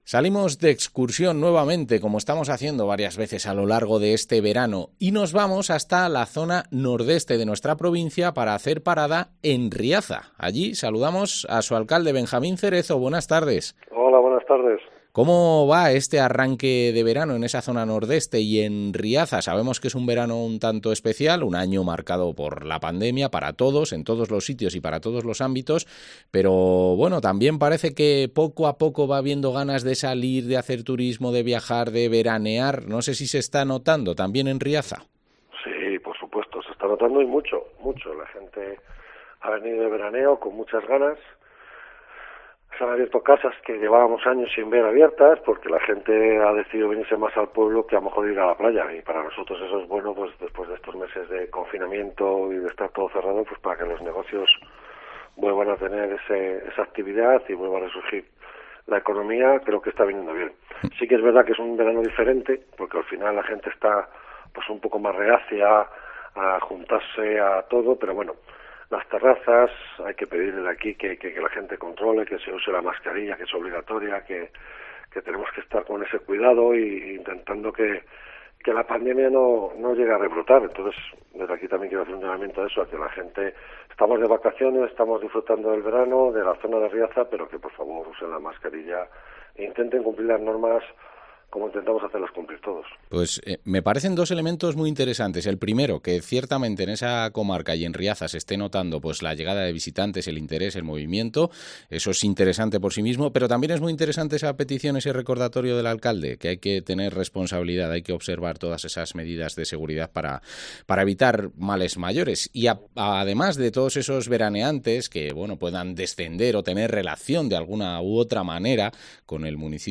Entrevista al alcalde de Riaza, Benjamín Cerezo